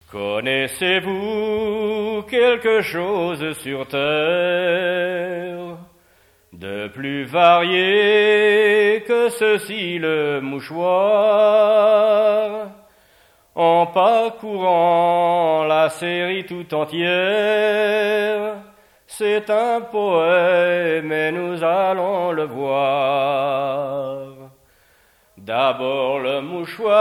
Genre strophique
chansons tradtionnelles
Pièce musicale inédite